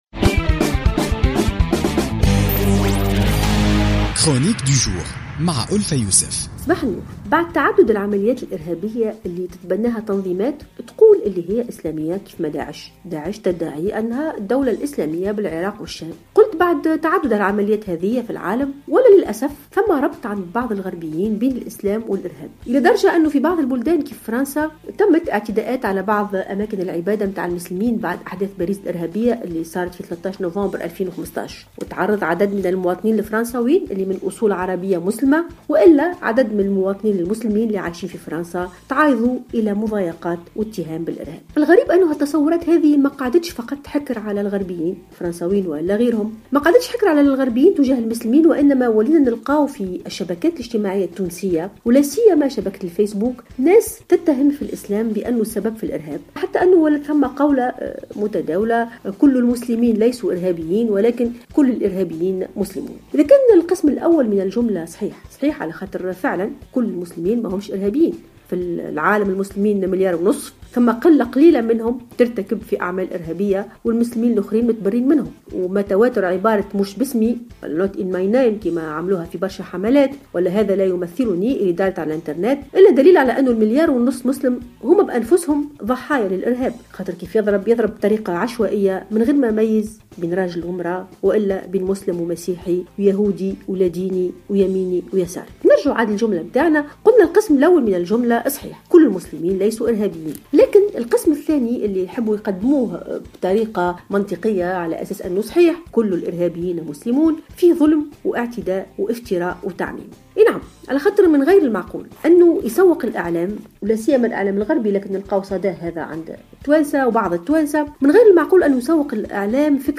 تحدثت الباحثة والأستاذة ألفة يوسف في افتتاحية اليوم الاثنين 28 مارس 2016 عن تسويق بعض من الغربيين لفكرة ارتباط الإرهاب بالإسلام خاصة مع تعدد العمليات الإرهابية من قبل تنظيمات تدعي أنها إسلامية مثل "داعش".